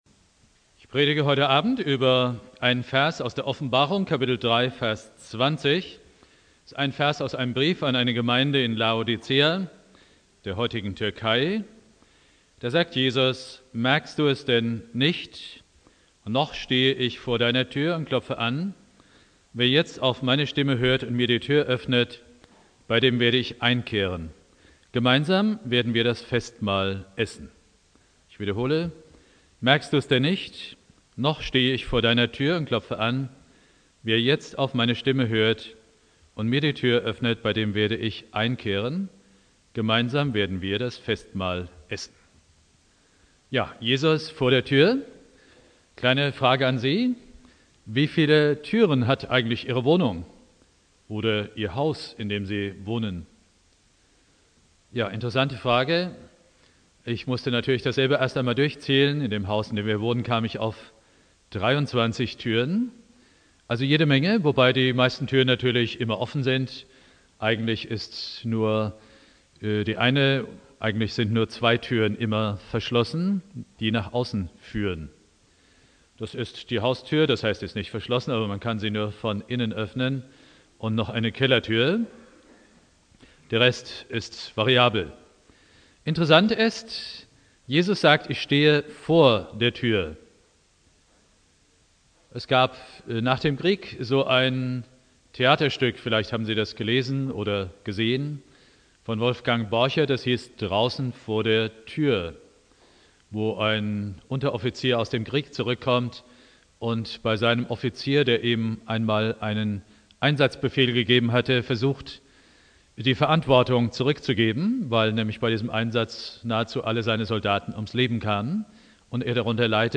Predigt
1.Weihnachtstag